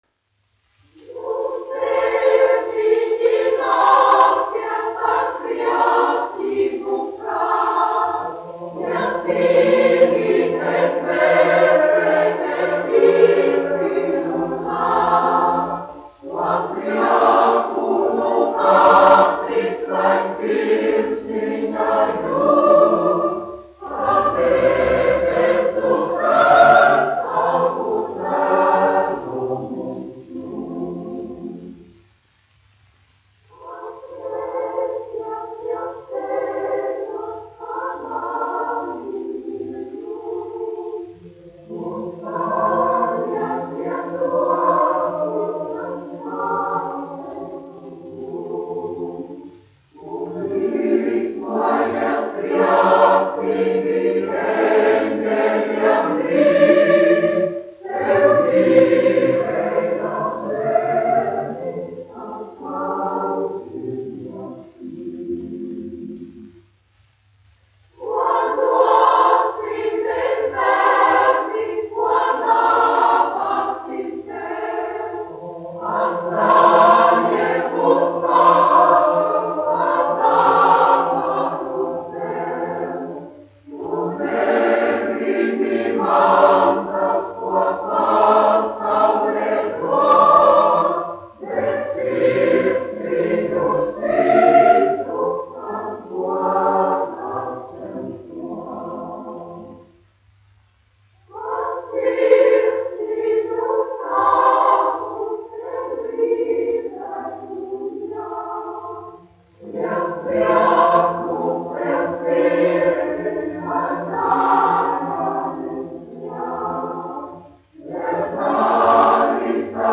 Reitera koris, izpildītājs
1 skpl. : analogs, 78 apgr/min, mono ; 25 cm
Ziemassvētku mūzika
Kori (jauktie)
Latvijas vēsturiskie šellaka skaņuplašu ieraksti (Kolekcija)